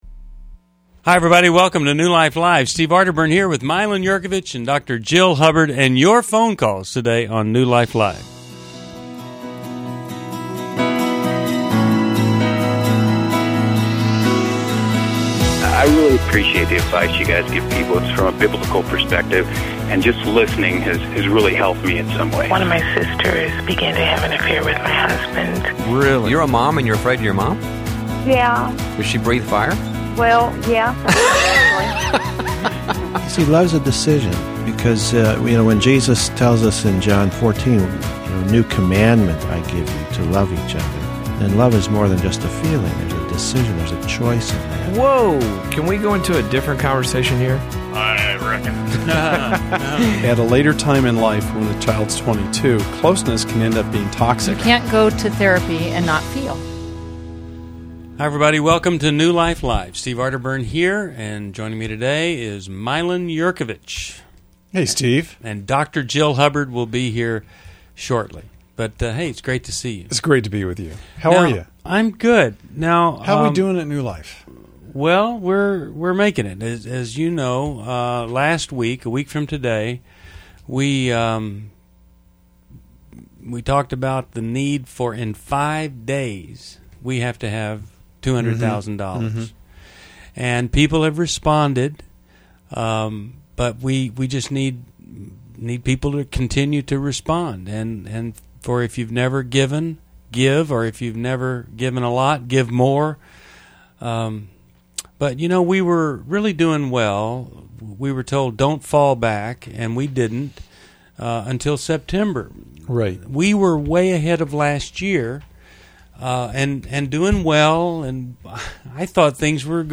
New Life Live: October 26, 2011 addresses grief from loss, the impact of abandonment, and personal growth through listener questions and expert insights.